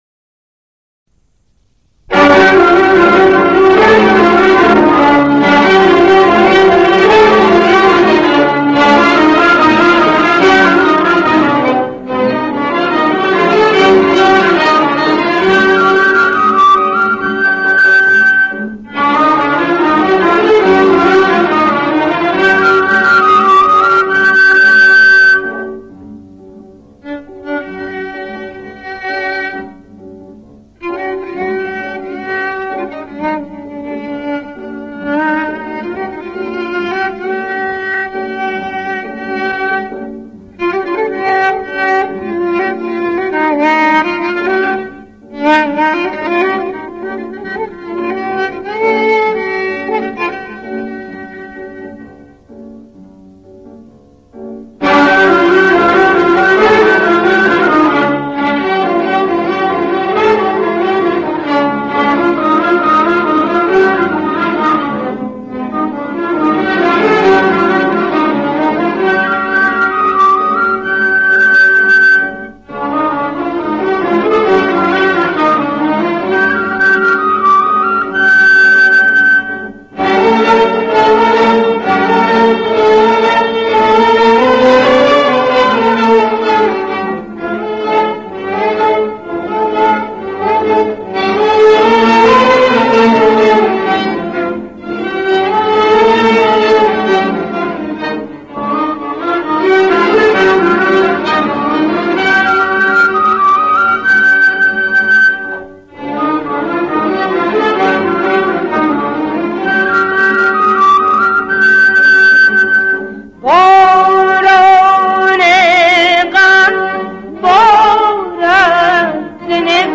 دستگاه: مخالف سه گاه